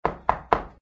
GUI_knock_4.ogg